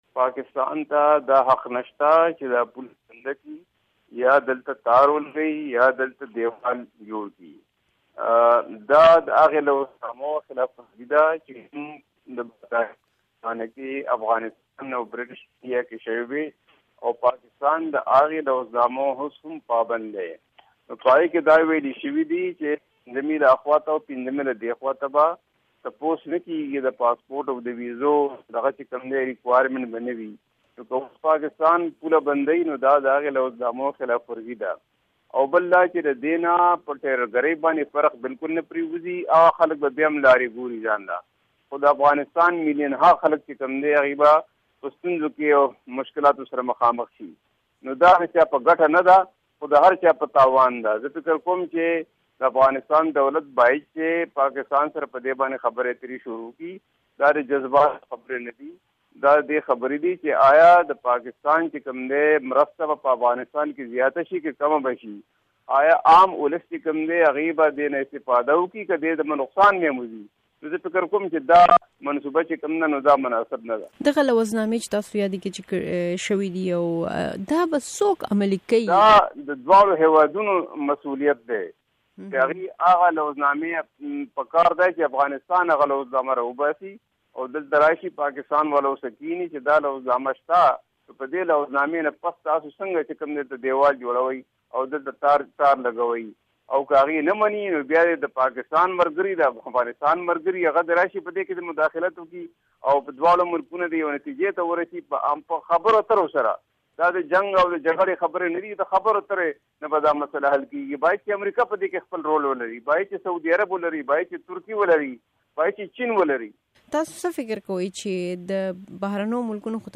د رستم خان مومند سره مرکه